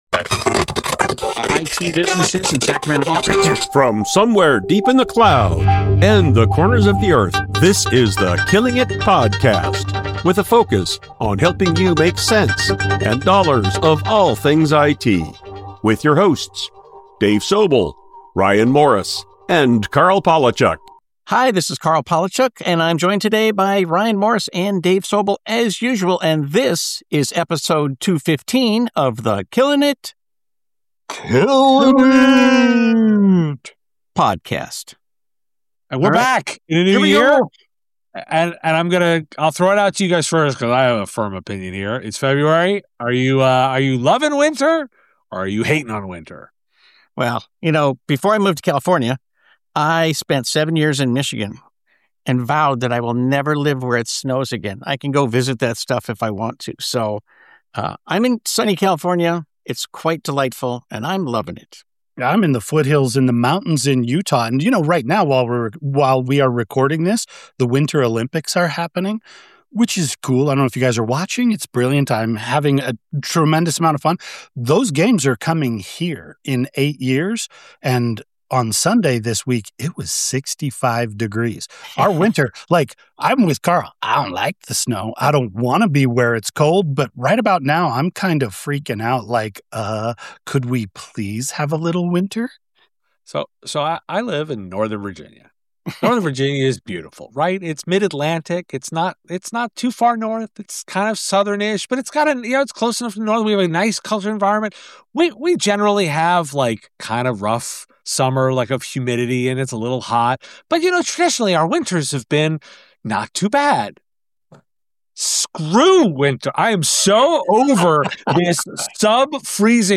The conversation quickly shifts gears to tackle some of the biggest challenges—and opportunities—facing IT businesses today. The trio dives deep into the global arms race for electricity, highlighting China's explosive growth in energy capacity and exploring what it means for data centers, AI, quantum computing, and the MSP landscape.